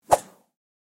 На этой странице собраны звуки копья: удары, скрежет, броски и другие эффекты.
Проход копья вскользь